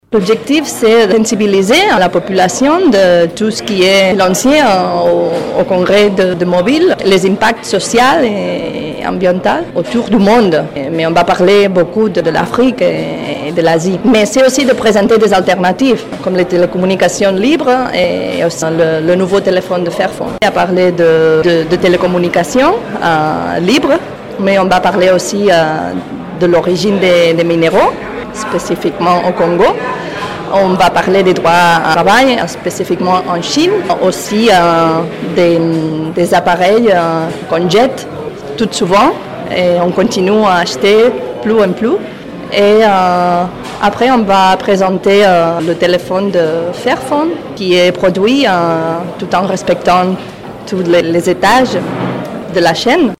entretien_congres_du_mobile.mp3